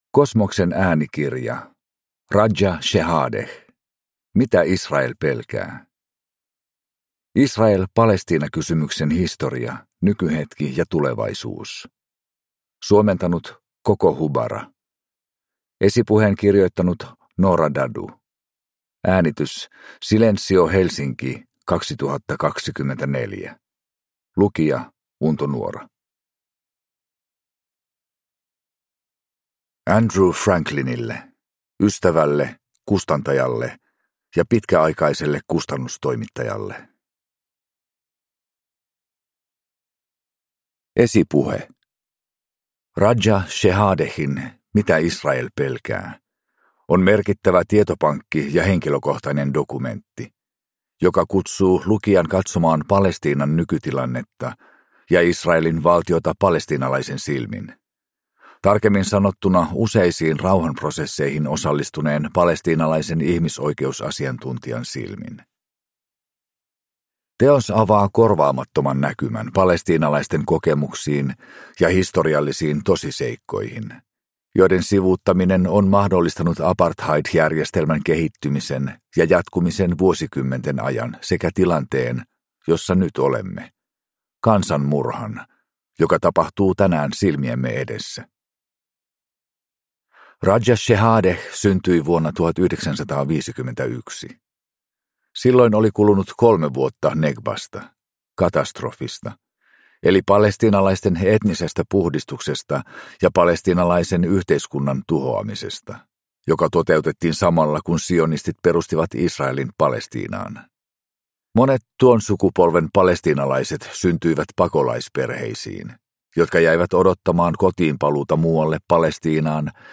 Mitä Israel pelkää? (ljudbok) av Raja Shehadeh